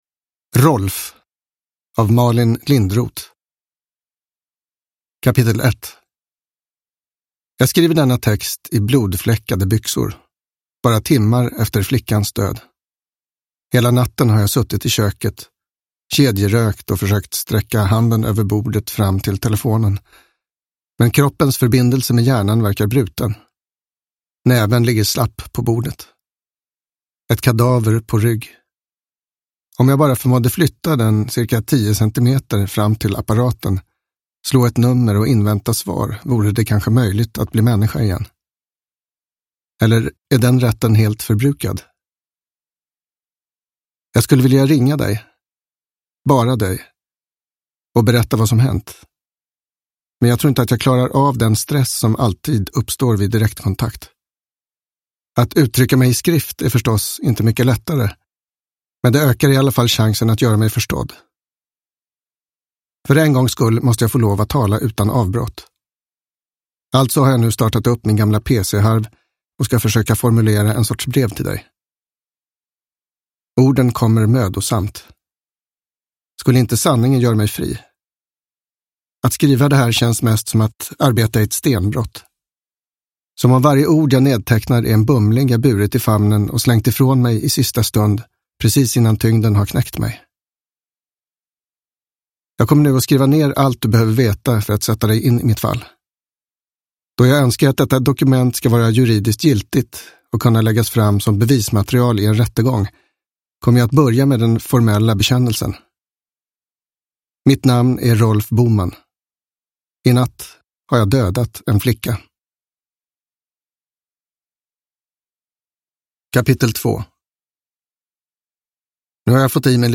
Rolf – Ljudbok – Laddas ner